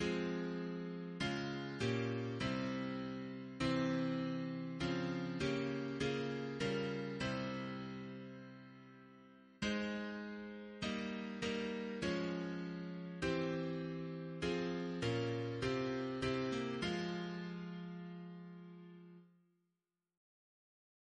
Double chant in F minor